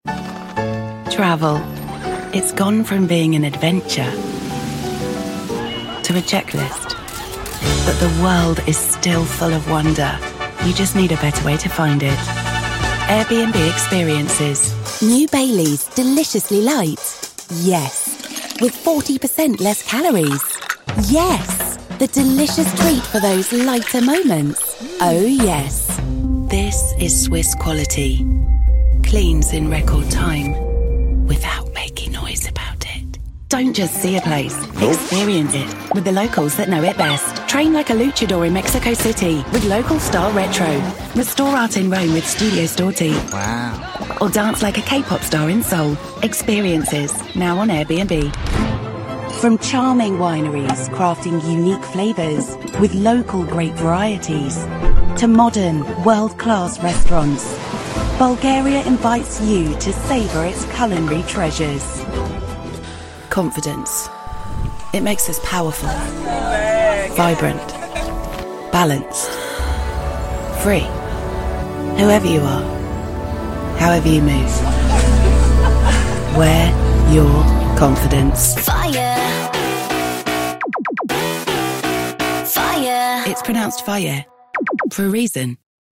eine warme, strukturierte britische Stimme mit subtiler Würde – klar, selbstbewusst und vielseitig, von der Generation Y bis zum Erwachsenenalter.
Kommerzielle Demo
Ihr Ton ist warm und strukturiert mit einem Hauch von Würde. Sie hat eine klare, selbstbewusste und verständliche Darbietung. Ihr Akzent ist neutral britisch, sodass sie je nach Publikum in der üblichen Aussprache oder in einem kühleren, urbaneren Stil sprechen kann.
Maßgeschneidertes Aufnahmestudio